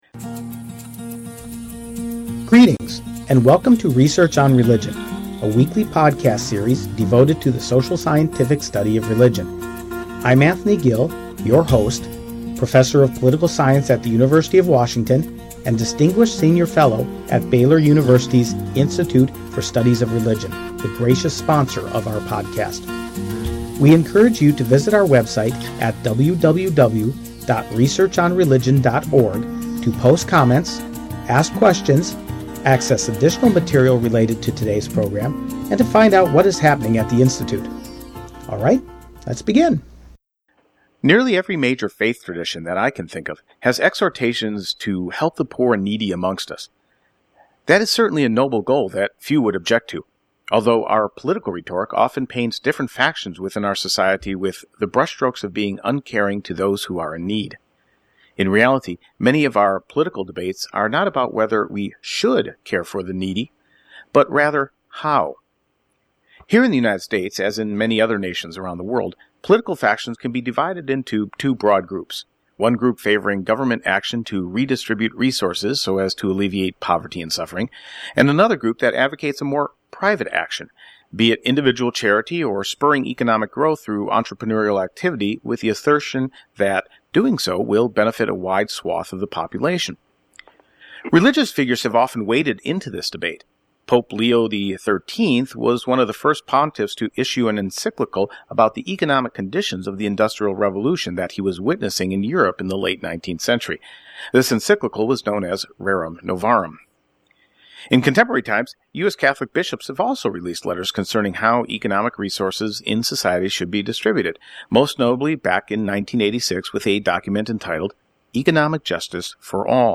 In the meantime please enjoy this popular interview from the past.